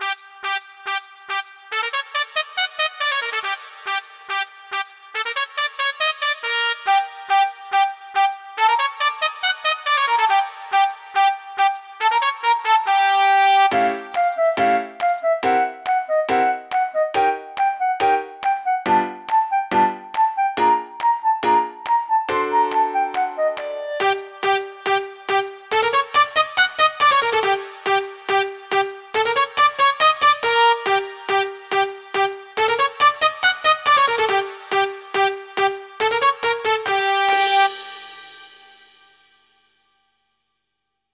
Character Fight Music, Theater Music
Festive-fart-music-3-faster-AN.wav